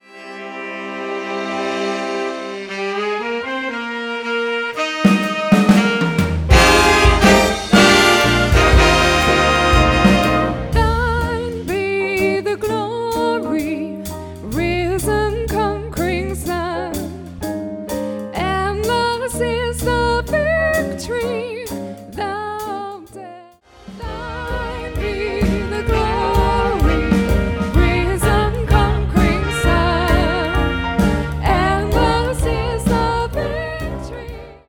A fantastic sing-along, swinging arrangement
with an extended drum solo.